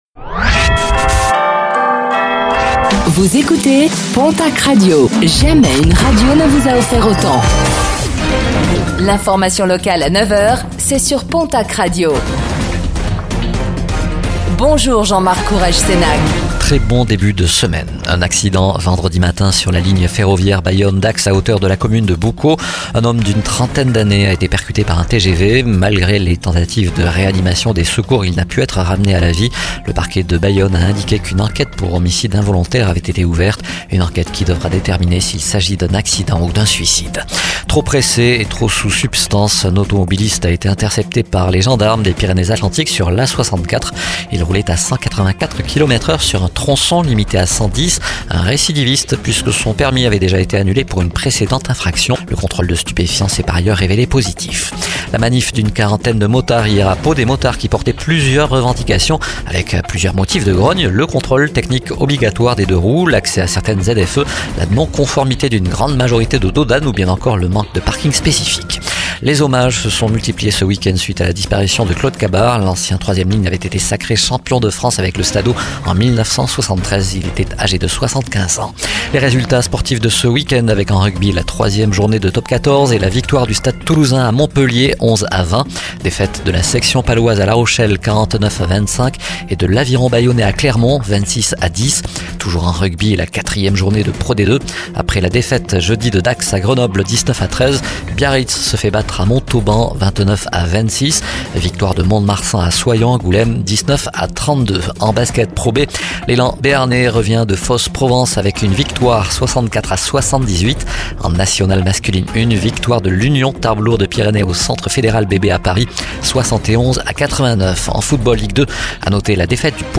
Infos | Lundi 23 septembre 2024
Réécoutez le flash d'information locale de ce lundi 23 septembre 2024